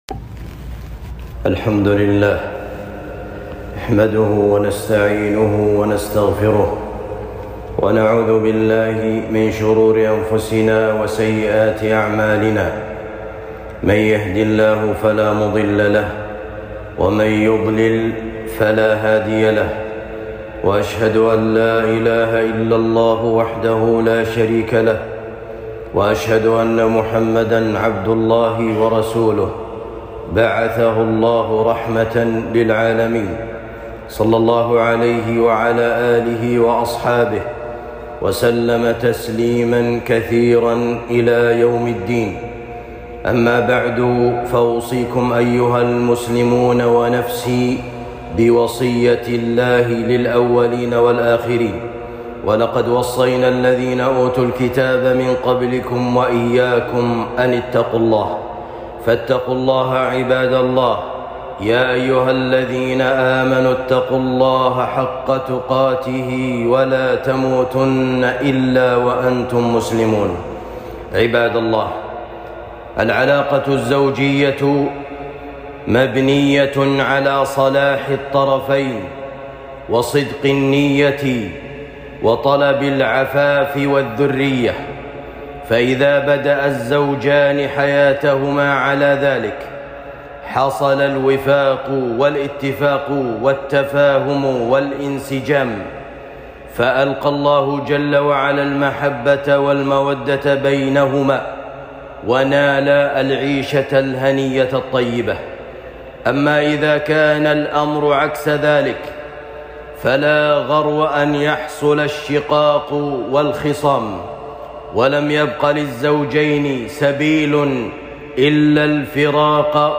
الطلاق أسبابه وعلاجه خطبة جمعة